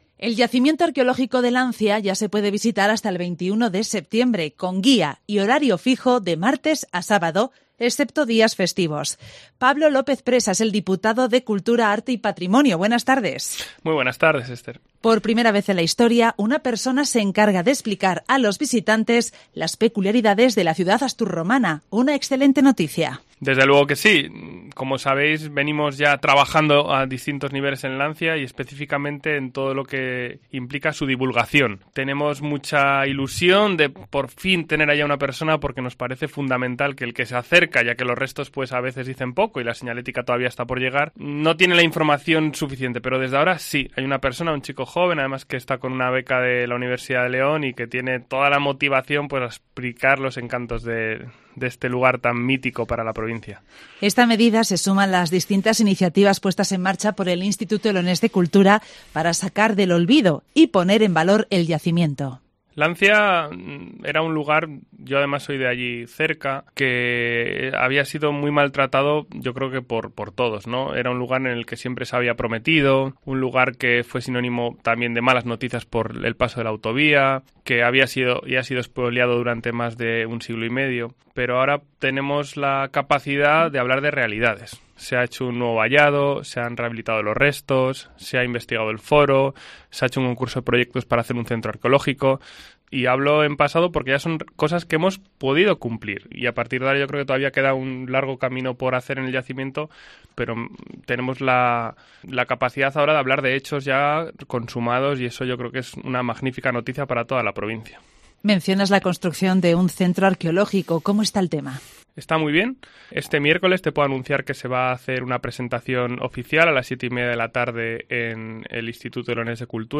Pablo López Presa, diputado de turismo, arte y patrimonio, explica en Cope León que el yacimiento arqueológico de la ciudad astur-romana de Lancia, uno de los enclaves patrimoniales más icónicos de la provincia, cuenta por primera vez en su historia con una persona que abre las puertas del recinto y traslada a los visitantes algunos detalles de este histórico lugar, en horario fijo y durante todo el verano.